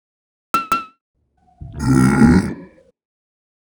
fire-2.wav